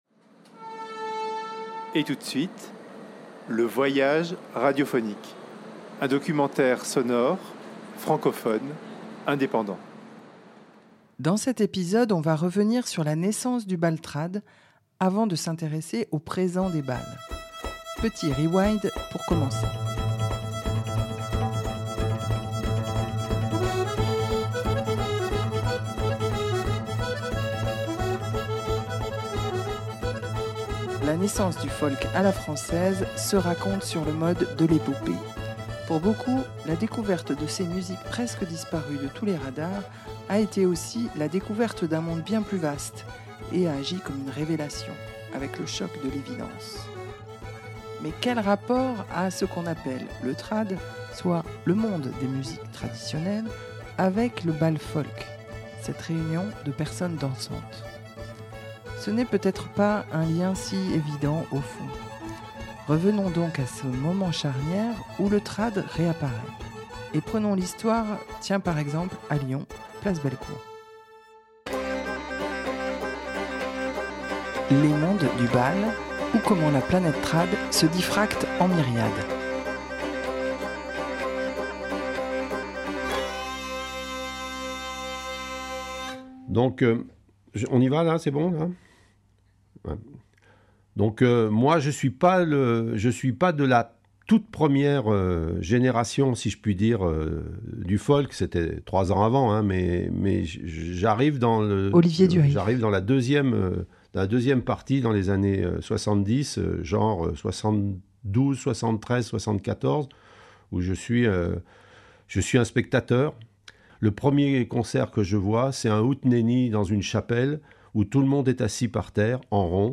Un documentaire